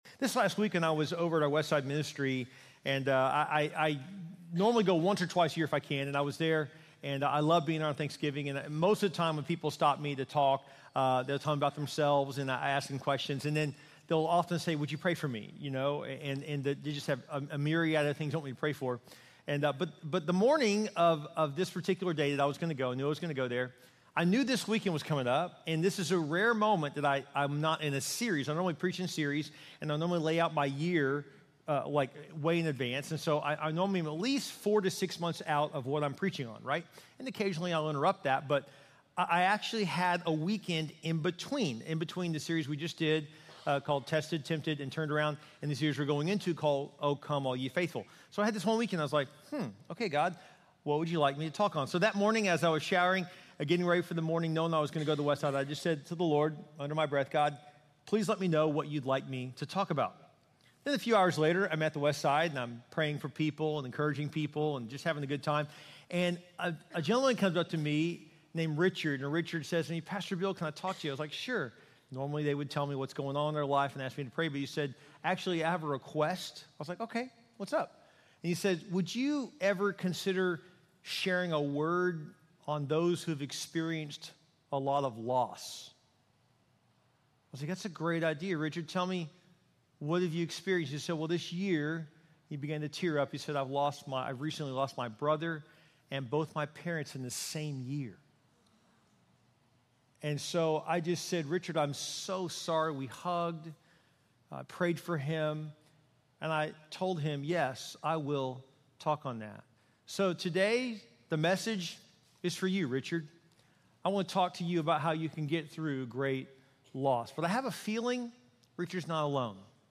Join us for a heartfelt sermon